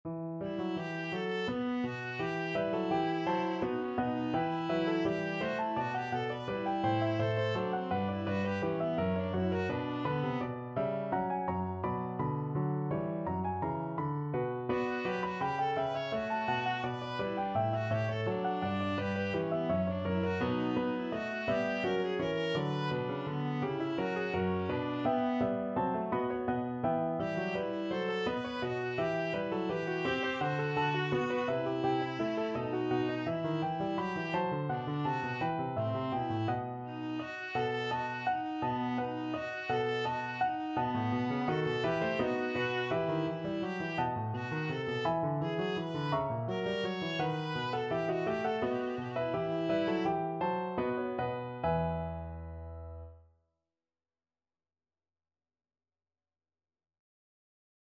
3/8 (View more 3/8 Music)
Classical (View more Classical Viola Music)